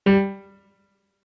MIDI-Synthesizer/Project/Piano/35.ogg at 51c16a17ac42a0203ee77c8c68e83996ce3f6132